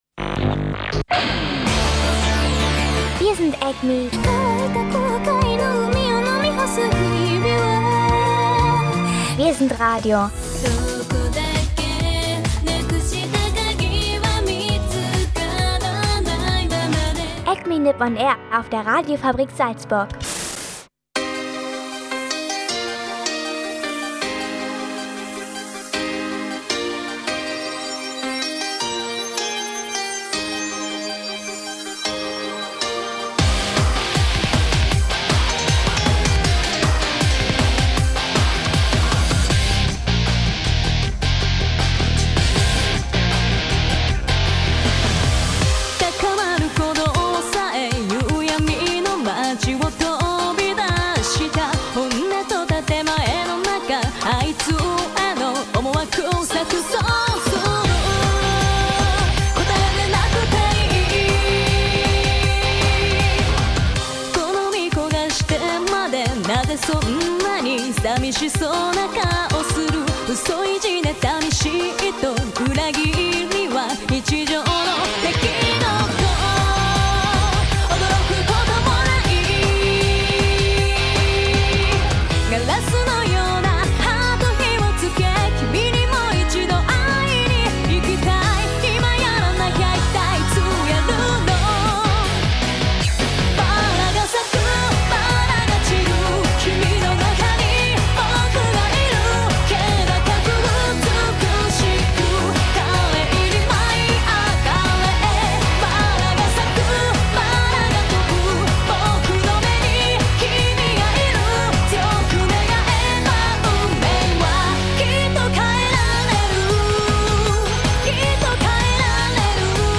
Beschreibung vor 8 Jahren Der zweite Teil zum AKIBA PASS Film Festival ist voller Filmtipps vom Festival. Über Fireworks und Mademoiselle Hanamura geht es bis zum sehr speziellen Mutafukaz um viel Anime-Kinoerlebnis. Und dazu gibts auch die passende Musik.